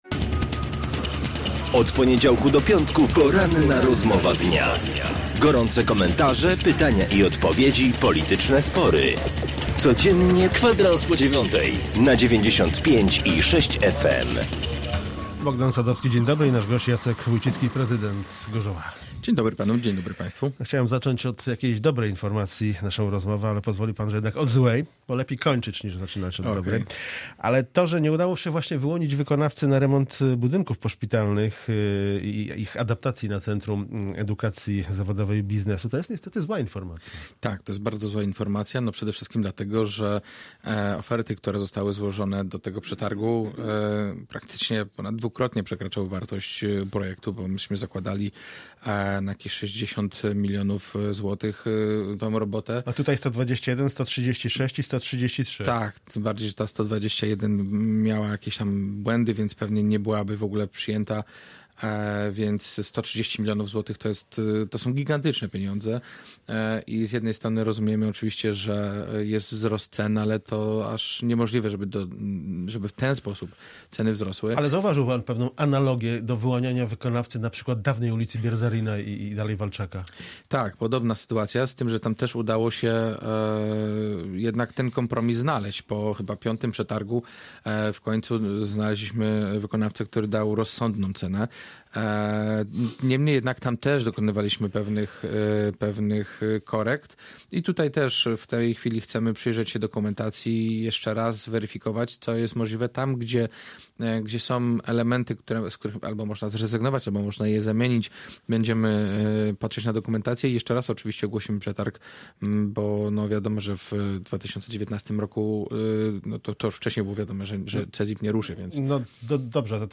Z prezydentem Gorzowa rozmawia